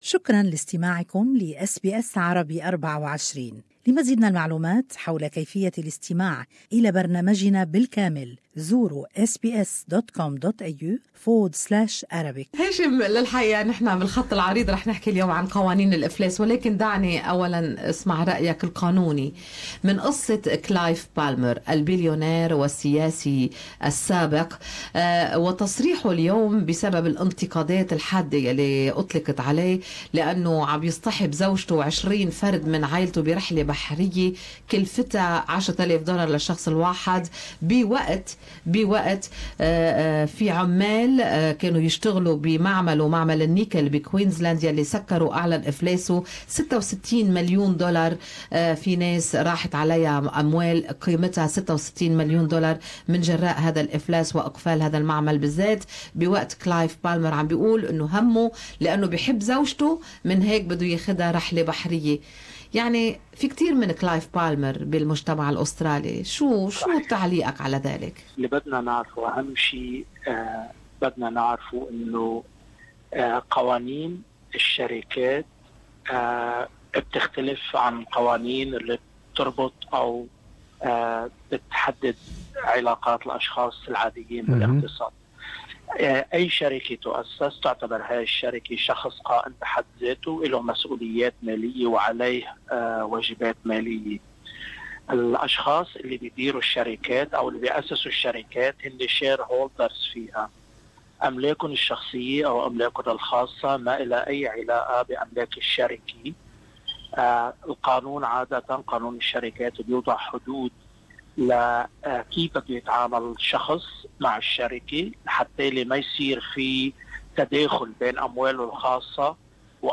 Good Morning Australia interviewed lawyer